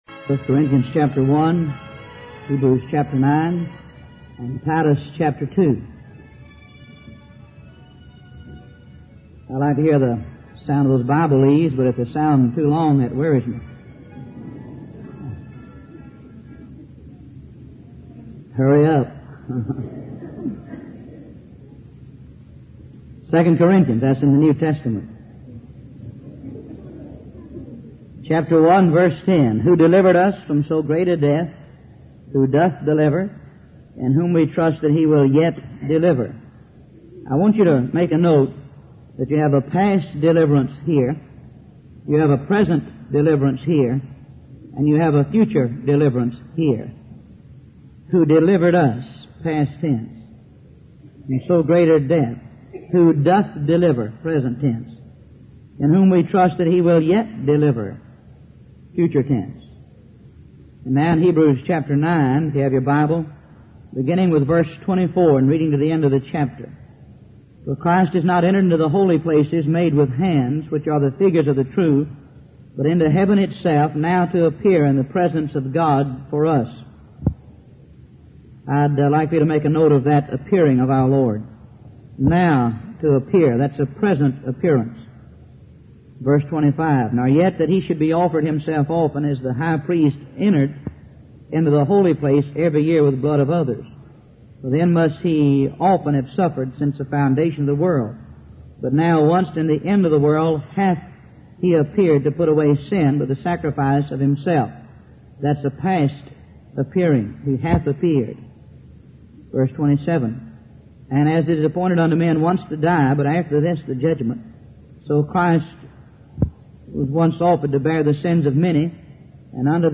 Professing themselves to be wise, they became fools. Wise Desire Ministries helps convey various Christian videos and audio sermons.